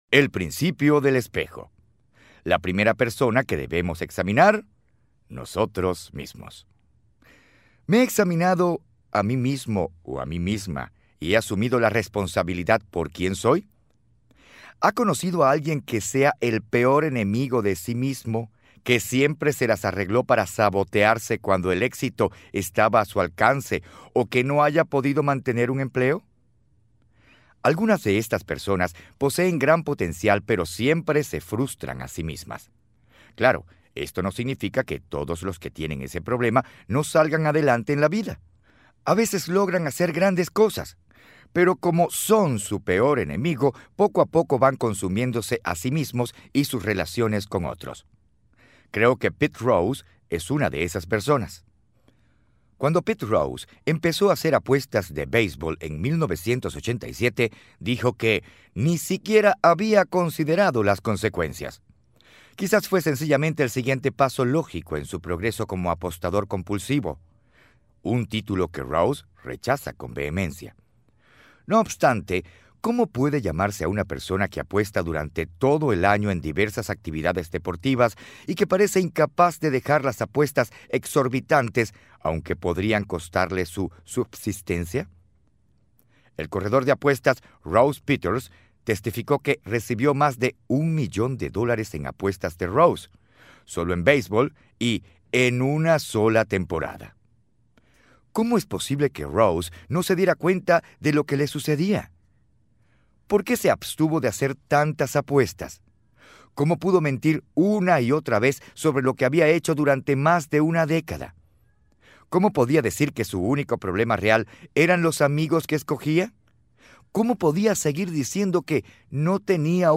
Audio Libros